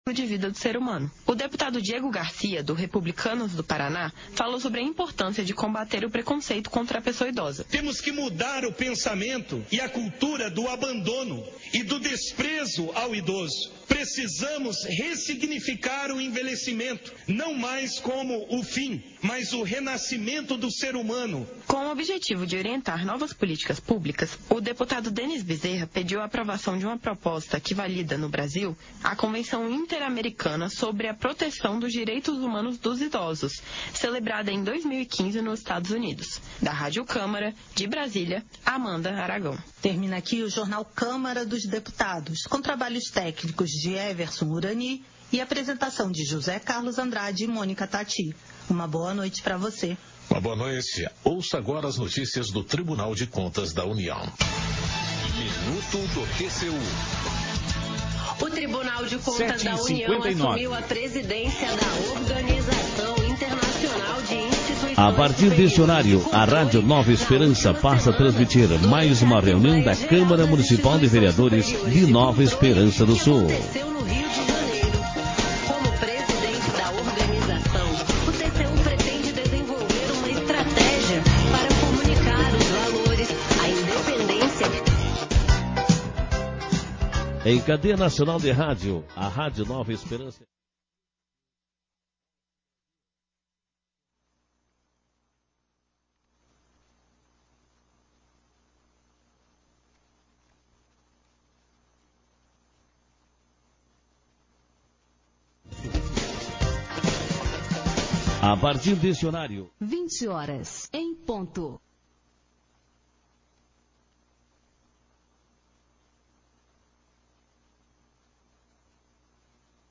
Sessão Ordinária 37/2022